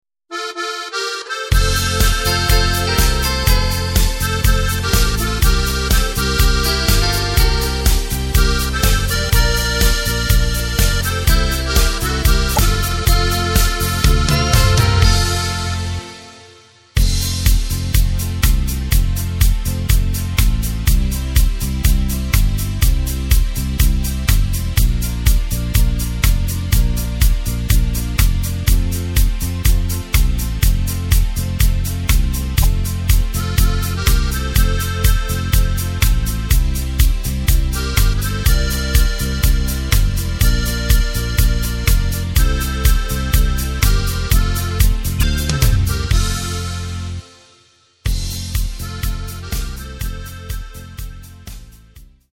Takt:          4/4
Tempo:         123.00
Tonart:            F
Playback mp3 Demo